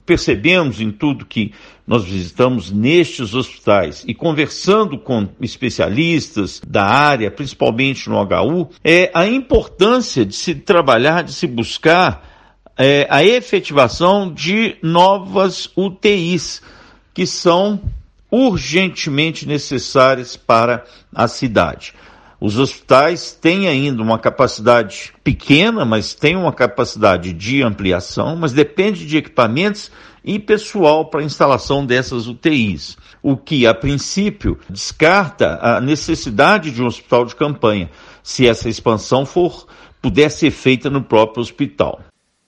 vereador José Márcio